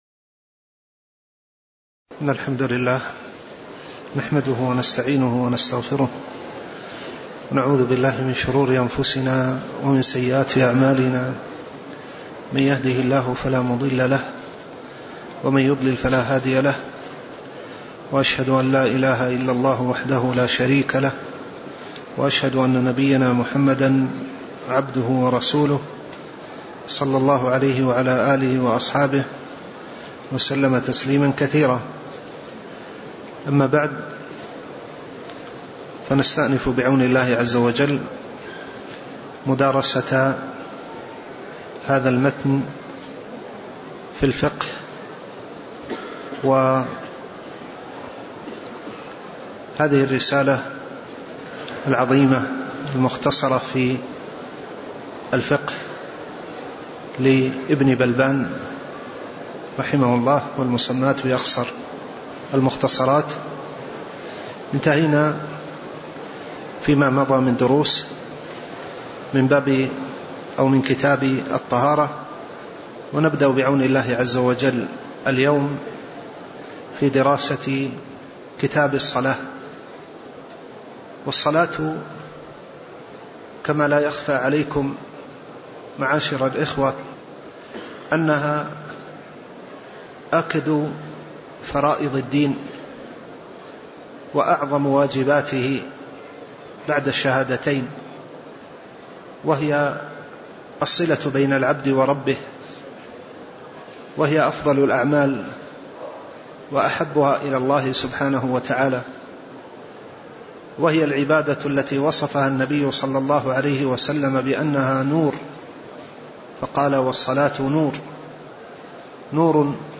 تاريخ النشر ٦ جمادى الأولى ١٤٣٩ هـ المكان: المسجد النبوي الشيخ